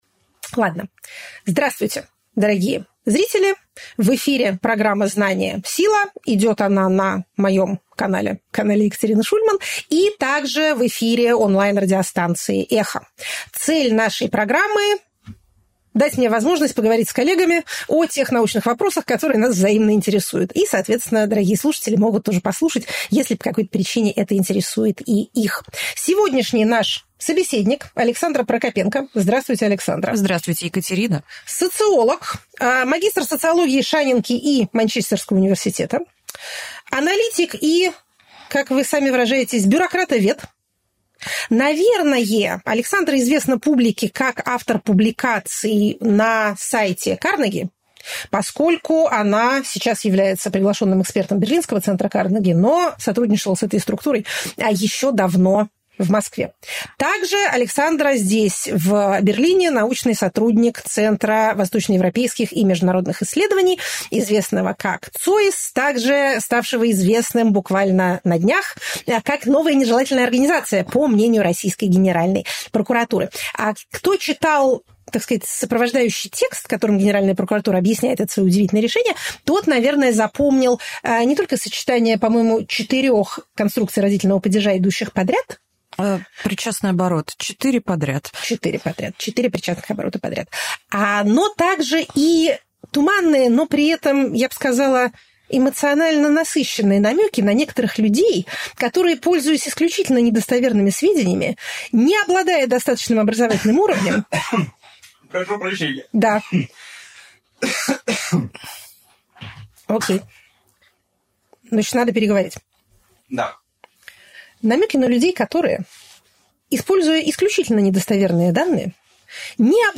Екатерина Шульман политолог